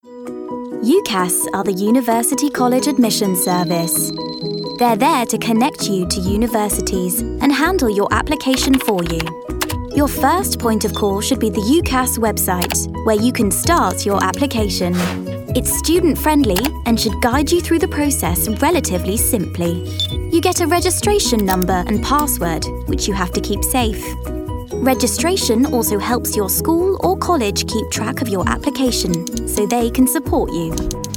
Narration Reel
• Native Accent: Standard English
A naturally youthful quality gives her access to a variety of teen voices, making her an easy choice for animation and video games.